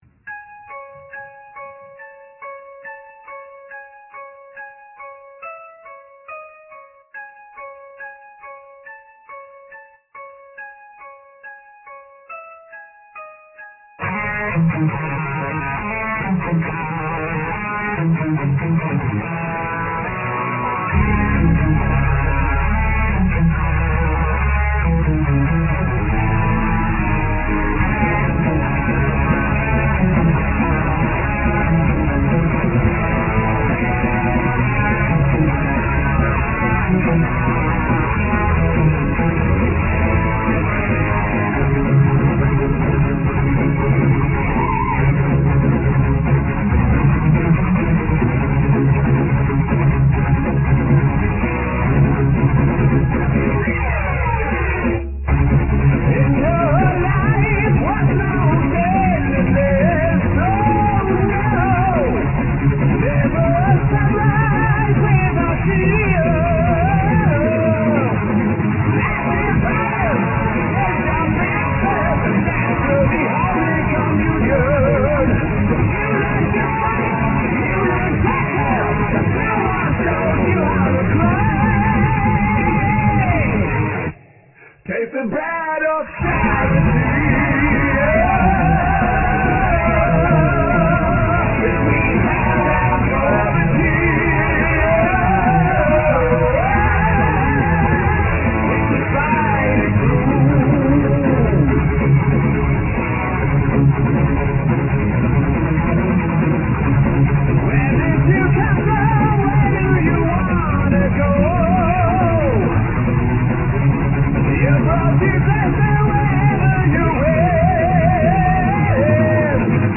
Vocals, Guitars and Sitars
Bass
Drums
Keyboards